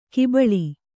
♪ kibaḷi